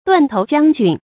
注音：ㄉㄨㄢˋ ㄊㄡˊ ㄐㄧㄤ ㄐㄩㄣ
斷頭將軍的讀法